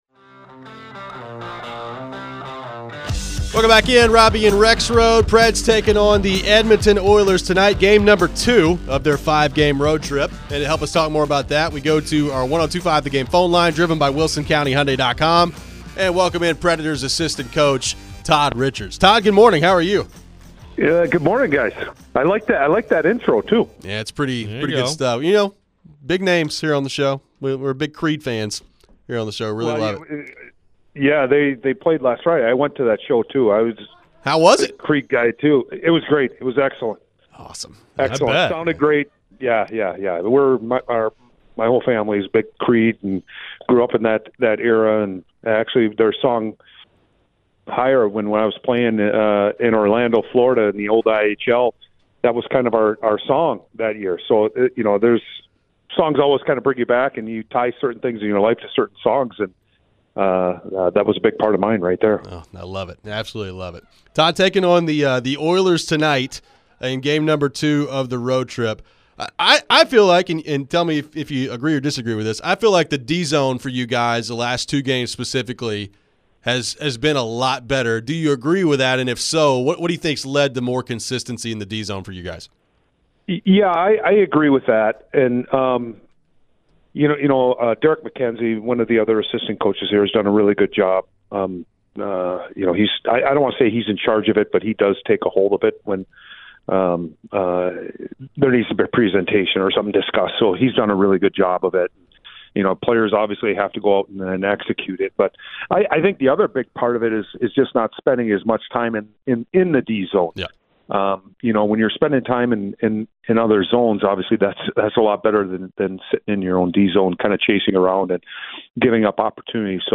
Todd Richards Interview (11-14-24)
Nashville Predators assistant coach Todd Richards joined the show to discuss the matchup against the Oilers tonight. How much confidence is the team building right now?